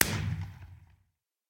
blast_far1.ogg